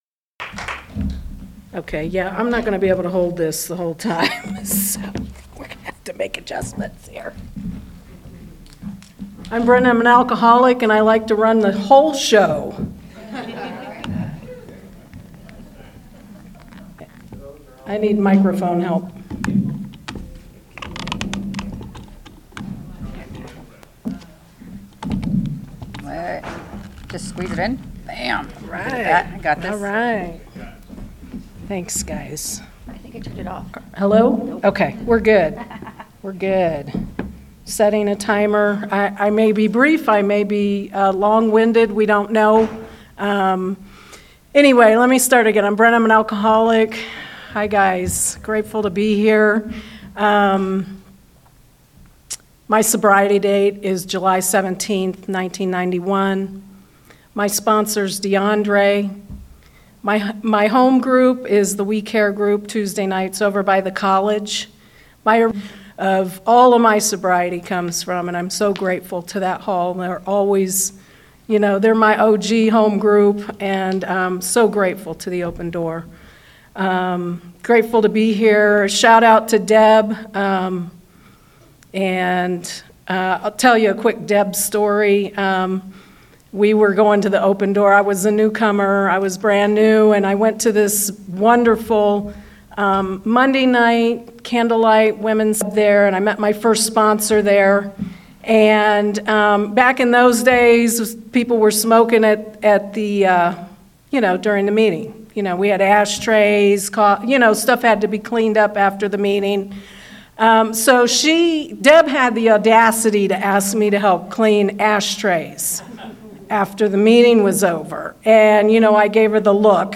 49th Annual Antelope Valley Roundup - Palmdale
Hometown Speaker &#8211